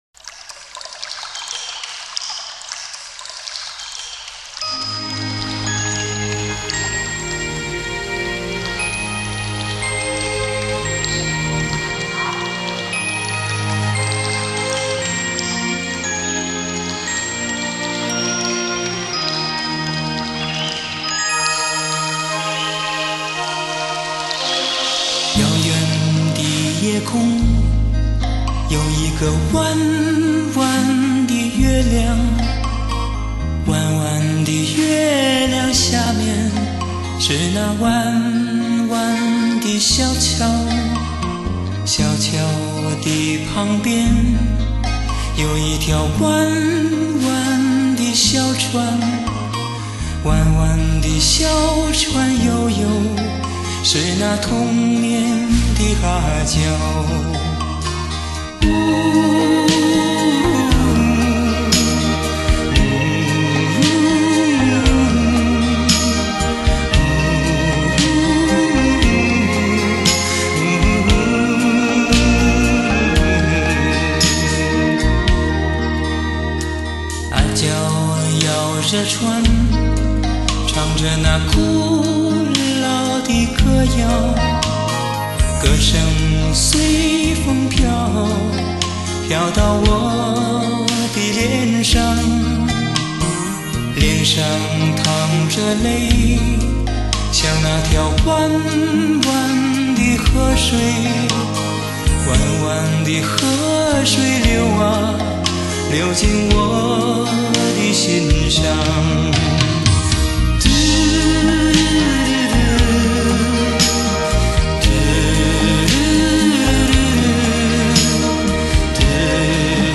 专辑类型：流行